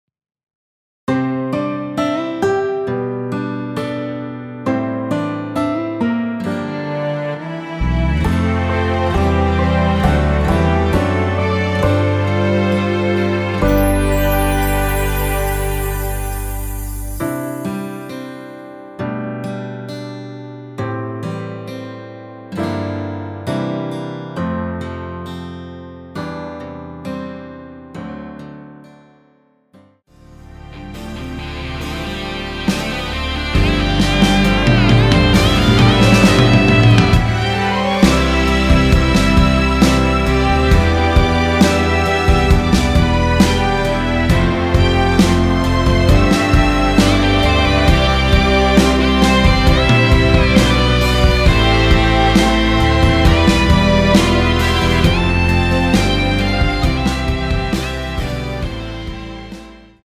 원키에서(-3)내린 MR입니다.
앞부분30초, 뒷부분30초씩 편집해서 올려 드리고 있습니다.